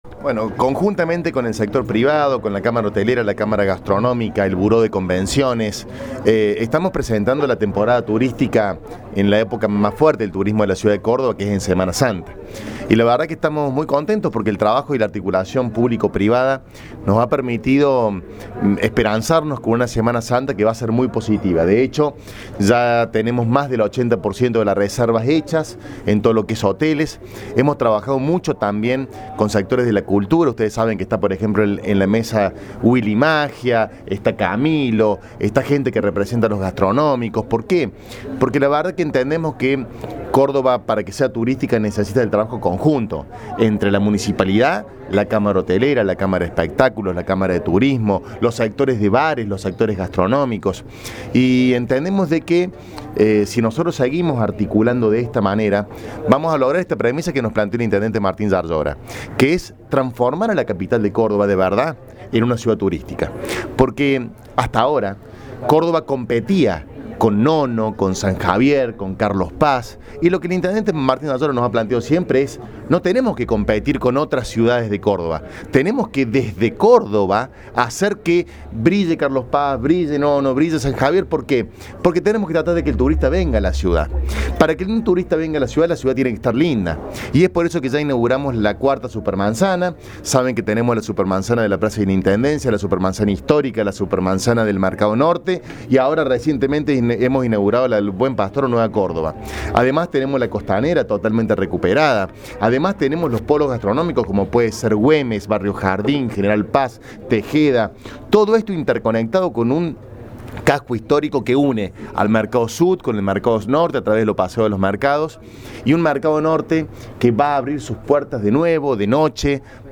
Audio: Miguel Siciliano (Sec. de Gobierno – Munic. Cba).